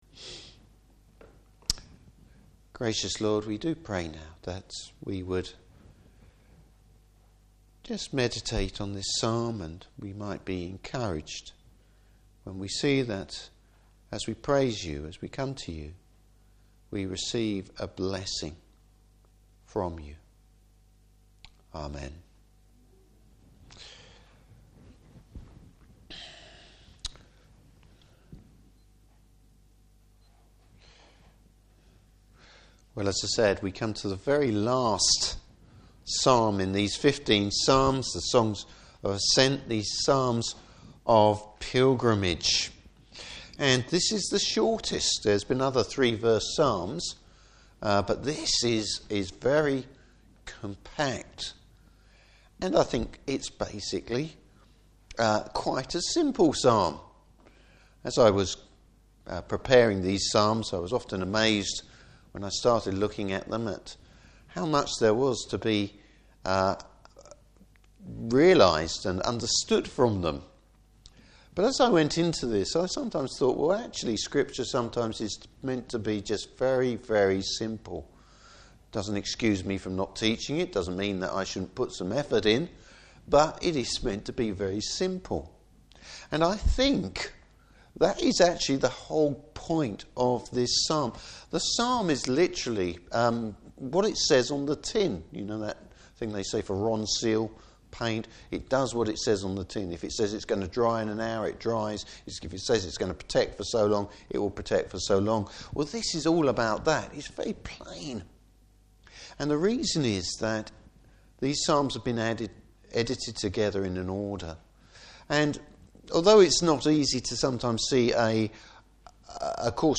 Service Type: Evening Service The blessing we receive through worship.